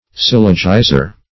Syllogizer \Syl"lo*gi`zer\